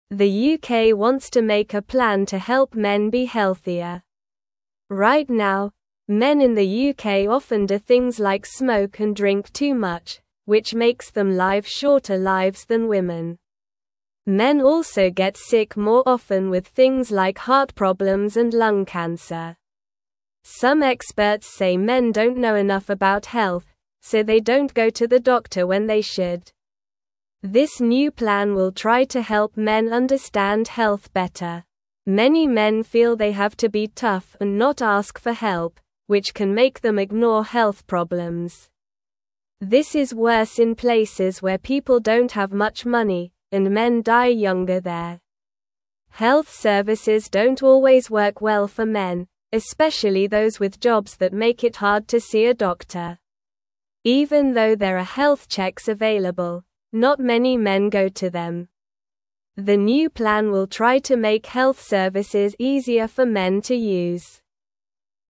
Slow
English-Newsroom-Beginner-SLOW-Reading-Helping-Men-Be-Healthier-in-the-UK.mp3